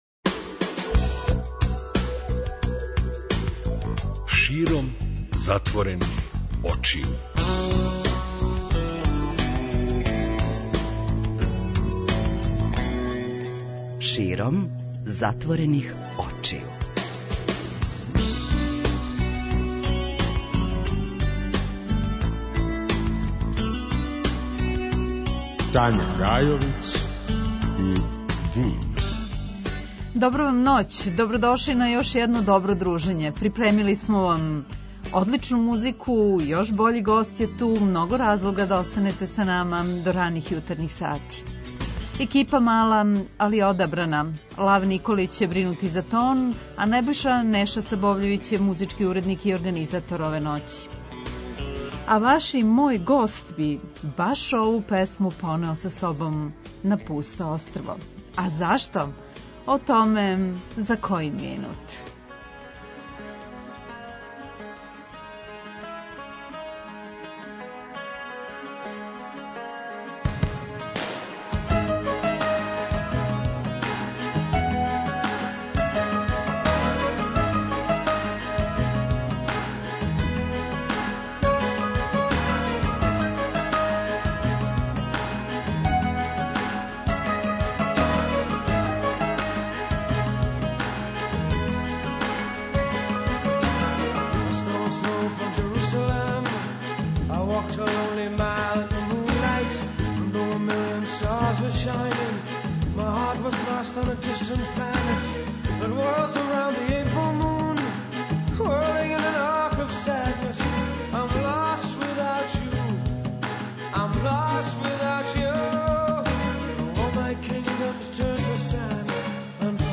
Зоран Кики Лесендрић, музичар, композитор и продуцент